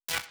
SFX_Static_Electricity_Single_01.wav